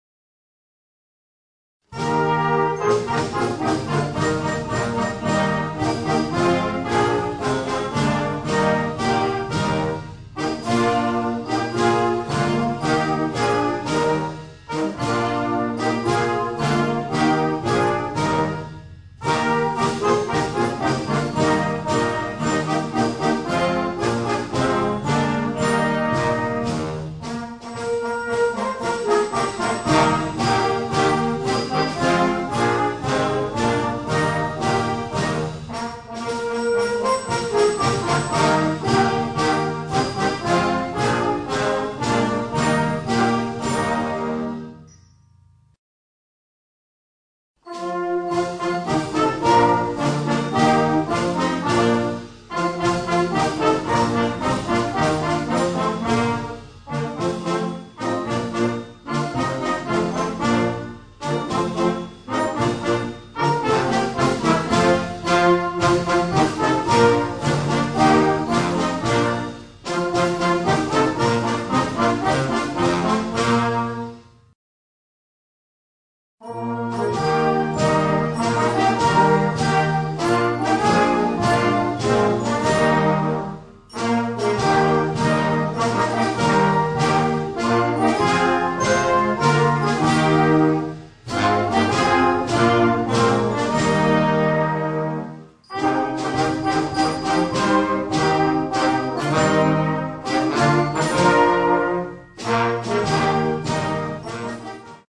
Sinterklaas und Weihnachtsmusik
Noten für flexibles Ensemble, 4-stimmig + Percussion.